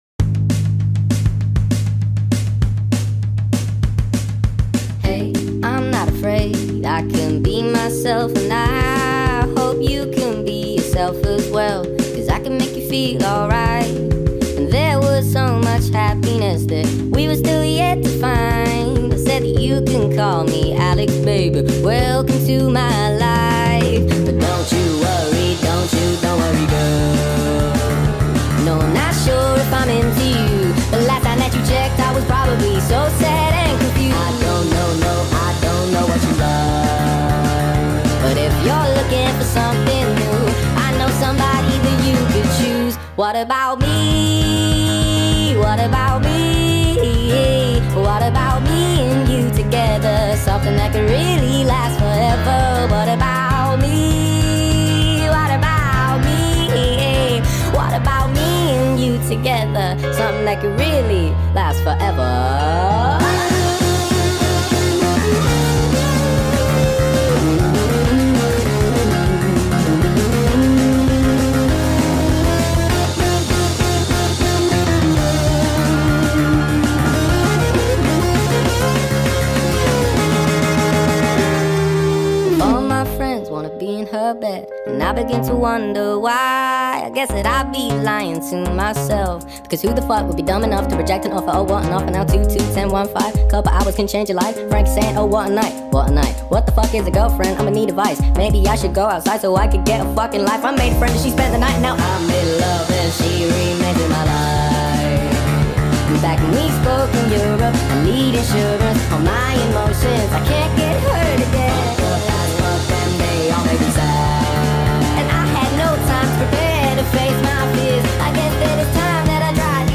نسخه Sped Up و سریع شده آهنگ
غمگین